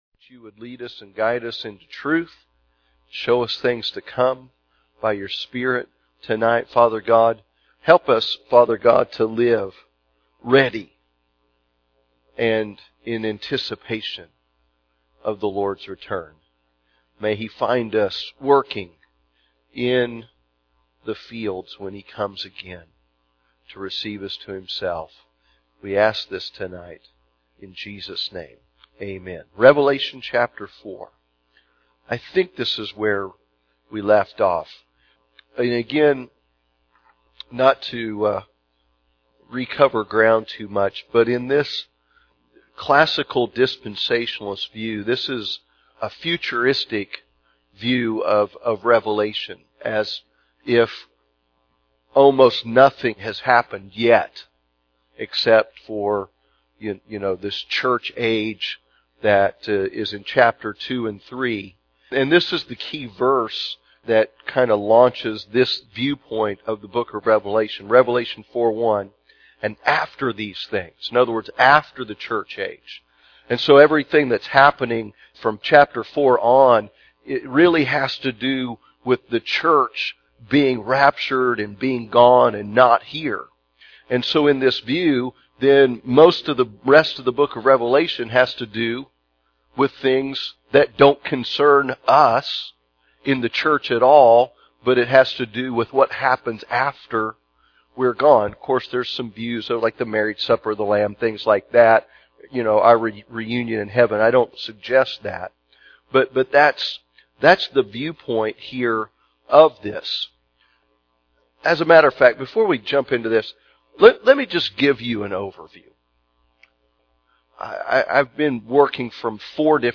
The Four Views of Revelation Wednesday Evening Service